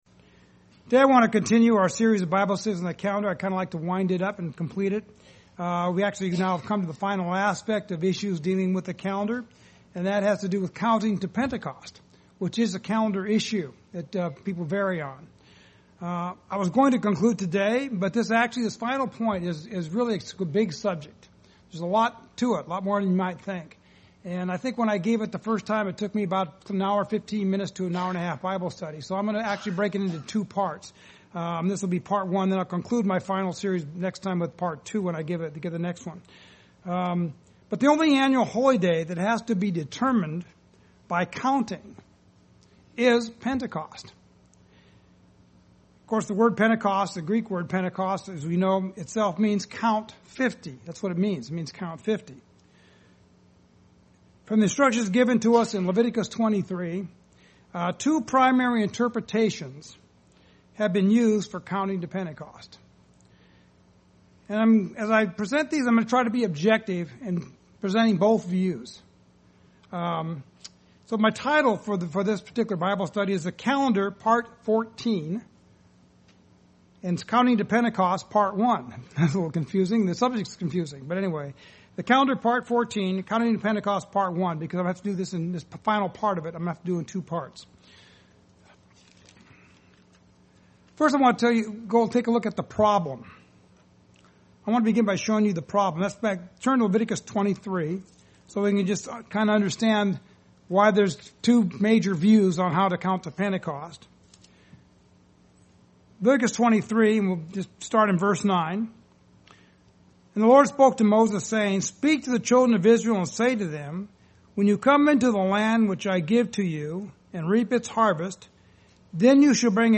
Part 1 Covers: Counting from the day after the First Day of Unleavened Bread UCG Sermon Transcript This transcript was generated by AI and may contain errors.